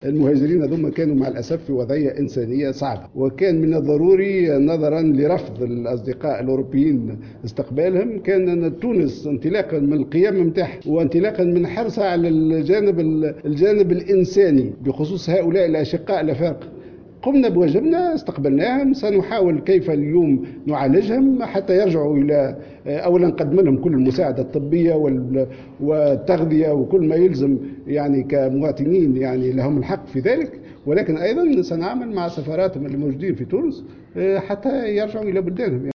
وأضاف في تصريح لموفدة الجوهرة اف ام، إثر استقباله اليوم الخميس بمقر الوزارة الأمين العام للاتحاد من أجل المتوسط ناصر كامل، أن الـ40 مهاجرا الذين تم استقبالهم كانوا في وضعية إنسانية صعبة وكان من واجب تونس إنسانيا انقاذهم.